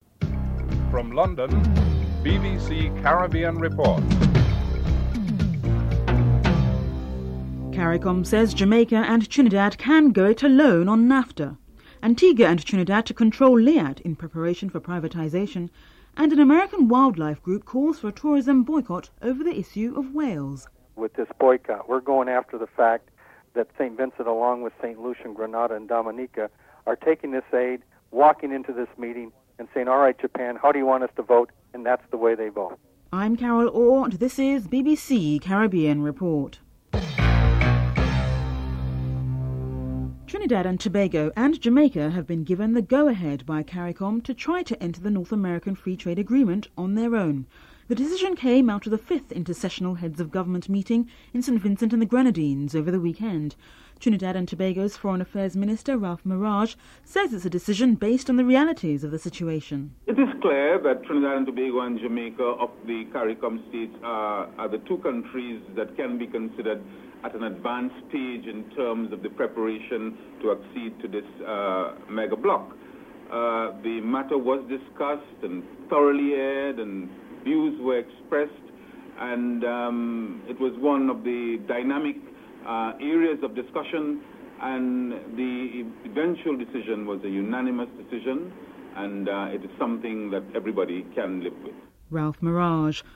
Trinidad and Tobago and Jamaica have been given the green light by CARICOM to enter into queue for the North American Free Trade Agreement (NAFTA) on their own. This decision was reached at the 5th Intercessional Meeting of the Heads of Government held in St Vincent and Grenadines. Foreign Affairs Minister of Trinidad and Tobago, Ralph Maraj comments on the decision (00:30-01:32)